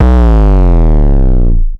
Index of /90_sSampleCDs/Zero-G - Total Drum Bass/Instruments - 1/track26 (Basses)
05 909 Down C.wav